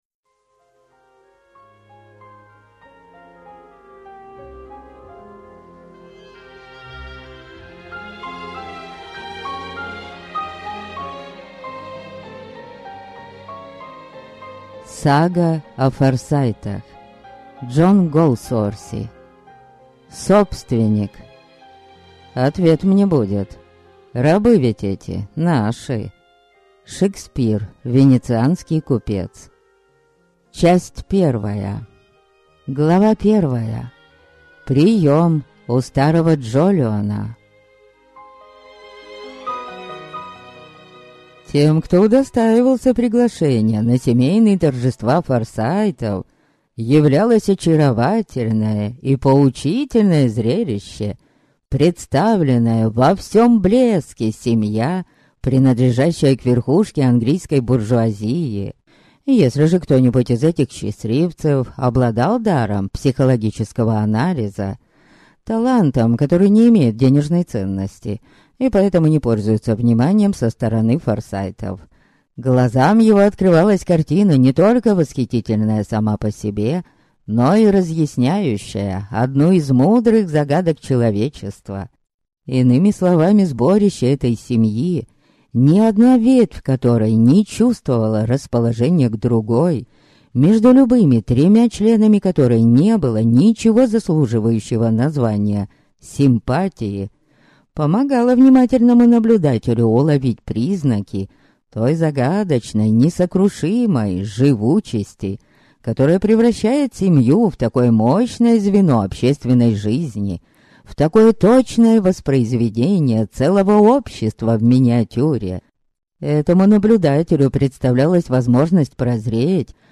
Аудиокнига Собственник | Библиотека аудиокниг